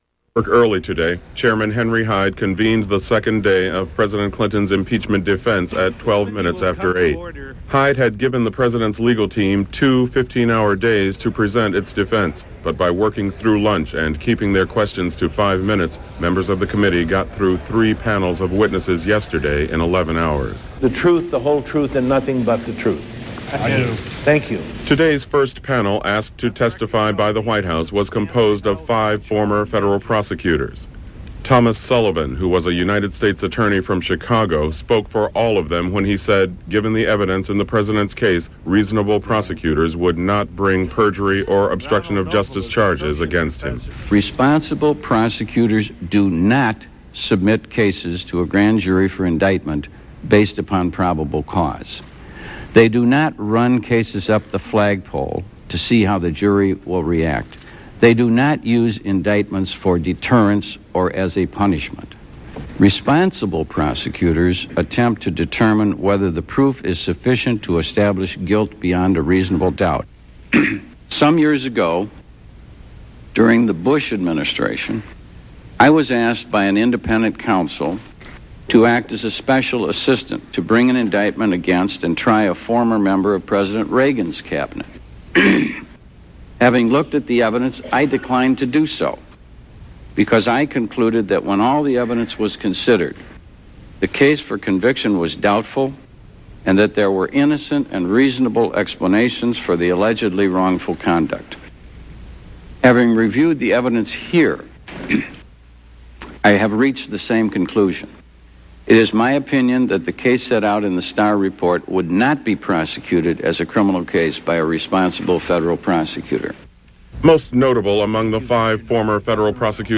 Broadcast on PBS-TV, News Hour, Dec. 9, 1998.